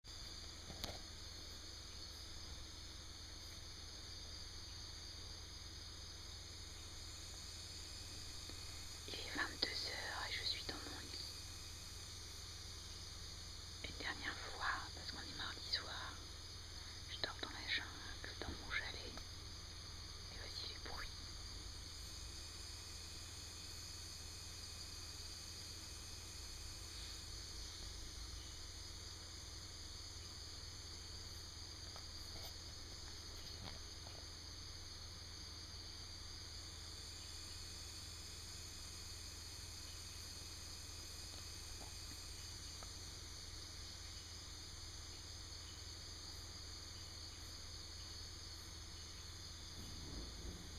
Cependant, une énigme persiste toutes les nuits : le bruit semble venir de ma chambre mais malgré une inspection en règle équipée de ma super torche, rien nada.
Pourtant ça grattouille de façon régulière.
bruits_de_la_nuit.mp3